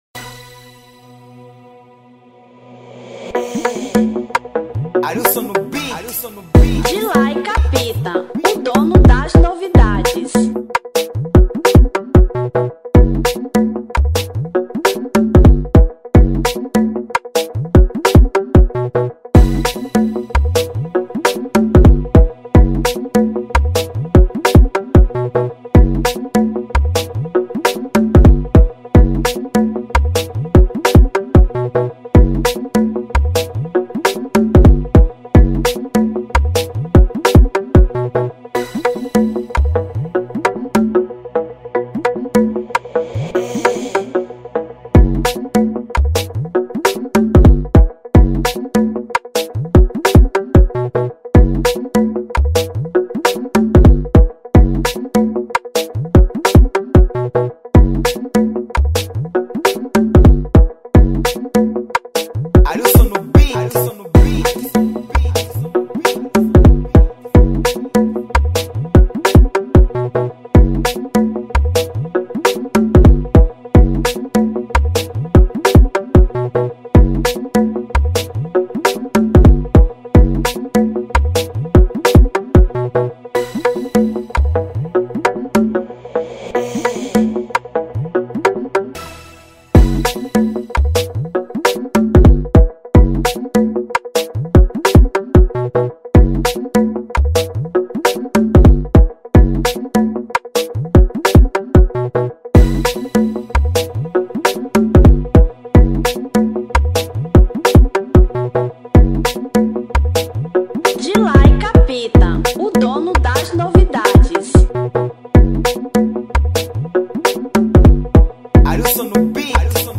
Instrumental 2025